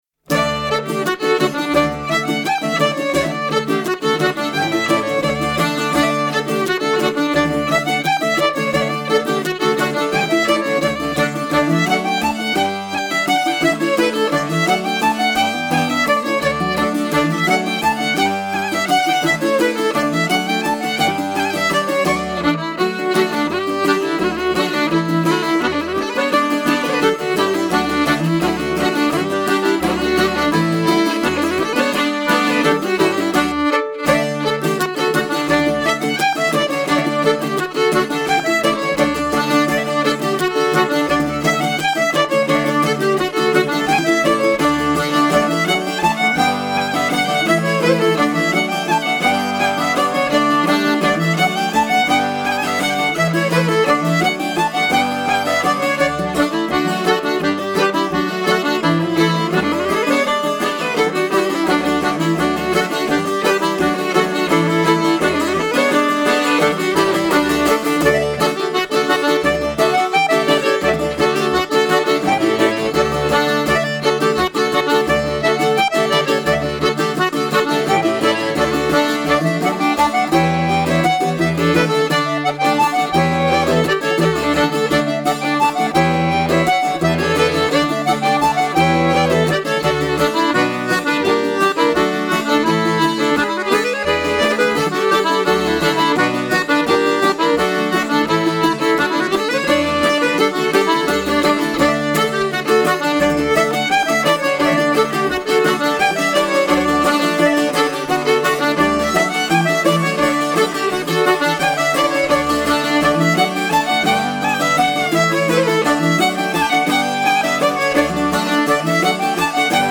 bouzouki & chant
violon & chant
trad wallon